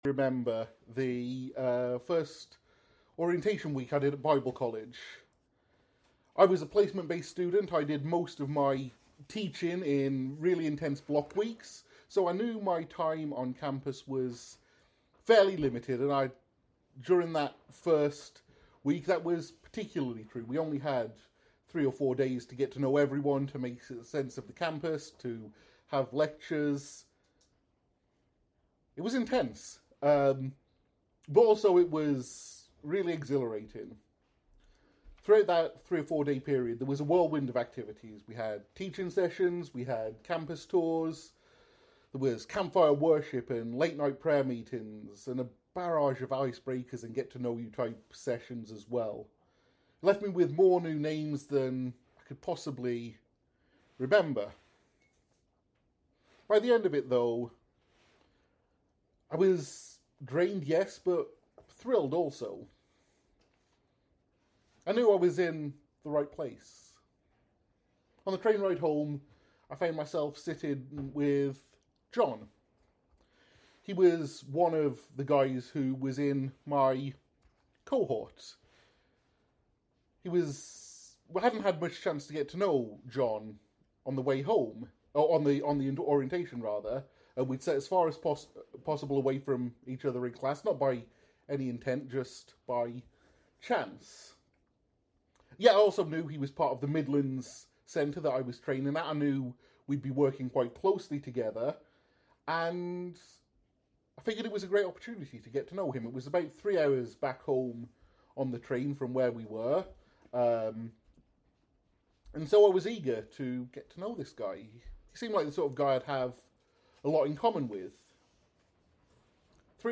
Finally, we introduce the ACTS prayer model — a simple, practical way to structure your prayer life — and take time to practice it together as a church family.